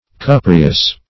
cupreous.mp3